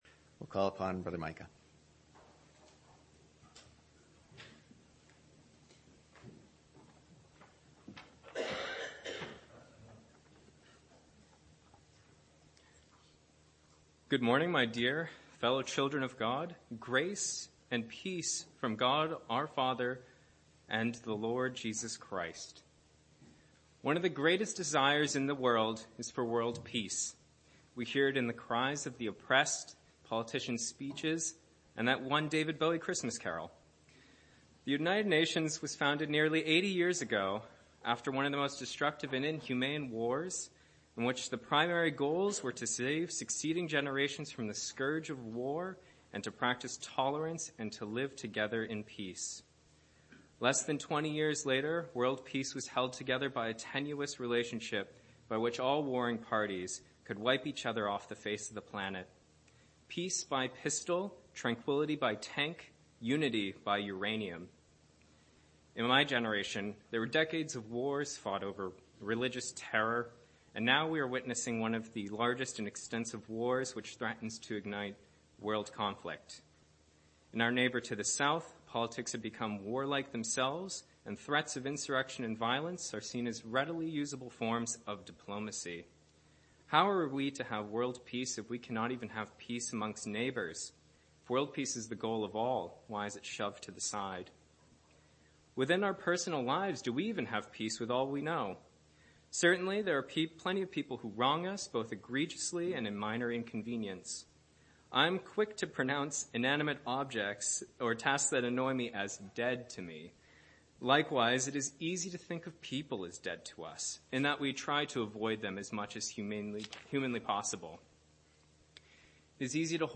Exhortation 11-13-22